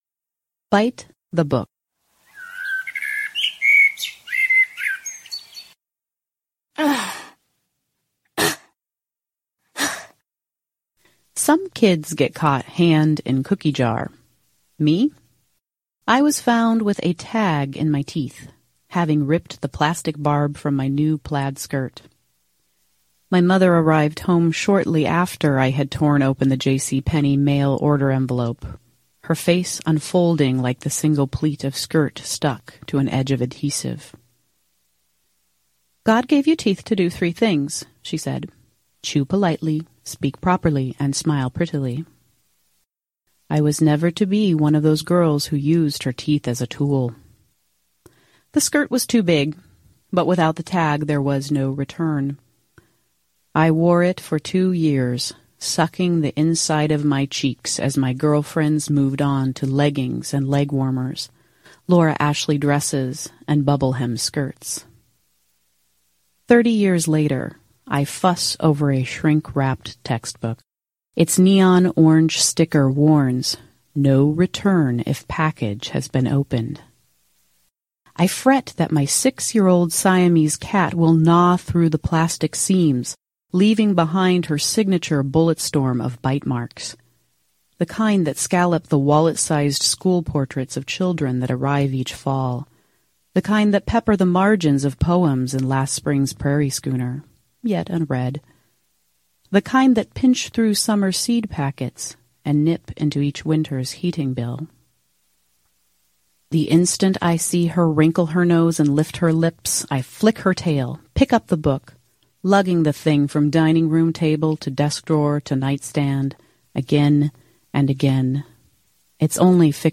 “Bite the Book” aired Thursday, April 30, 2015 as part of The Platte River Sampler.
The Platte River Sampler aired on Thursdays from 6PM – 7PM on 89.3 FM KZUM, Lincoln, Nebraska’s non-commercial, listener-sponsored community Radio Station.